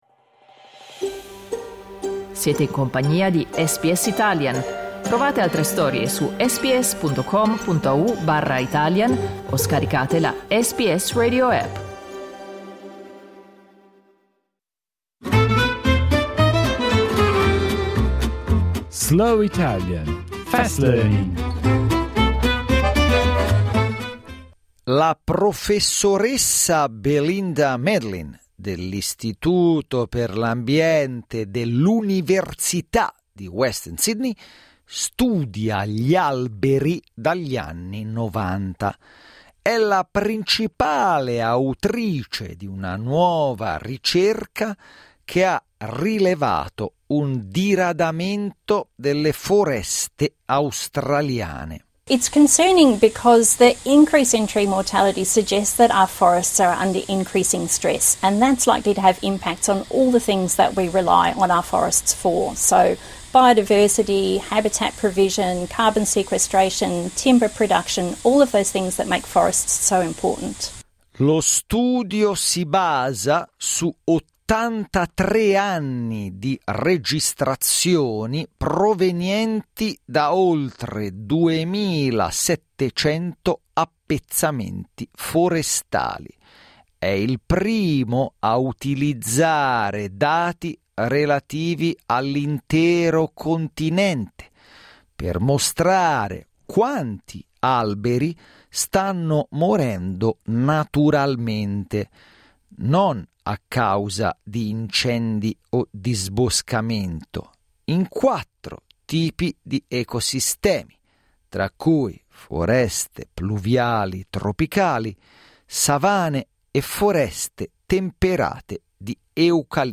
Slow Italian, Fast Learning